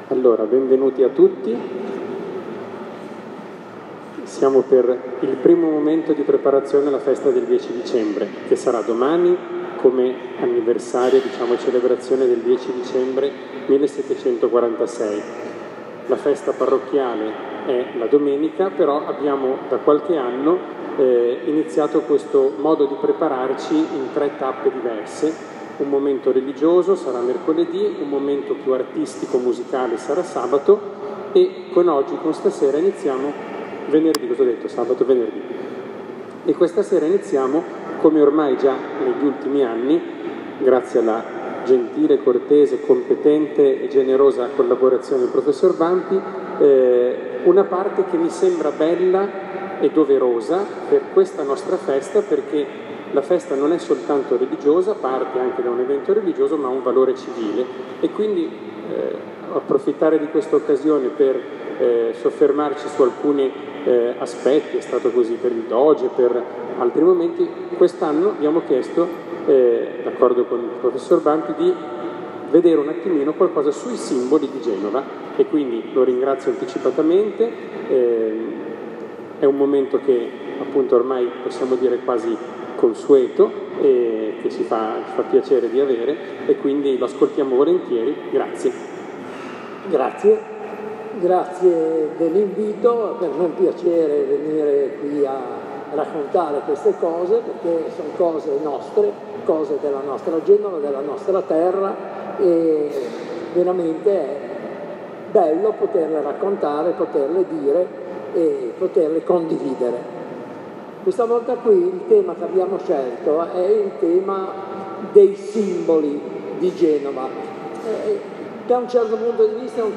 I simboli di Genova (audio della conferenza) | Santuario Nostra Signora di Loreto Genova Oregina